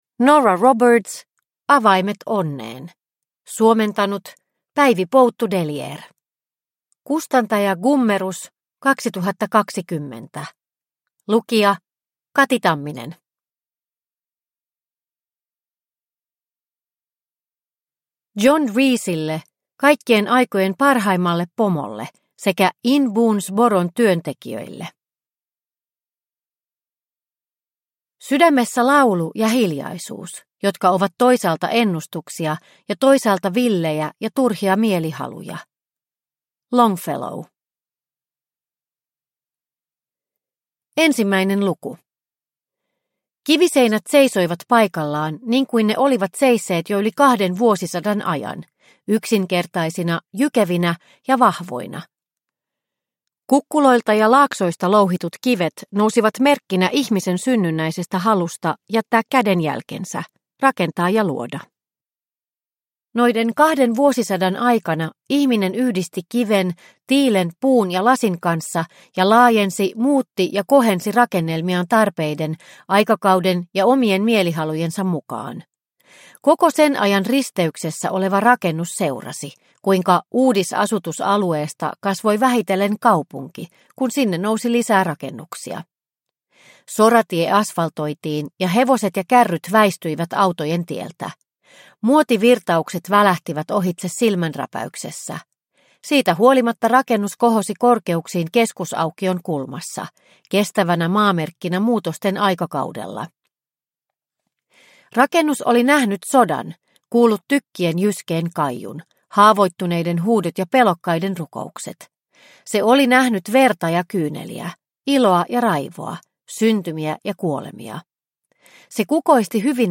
Avaimet onneen – Ljudbok – Laddas ner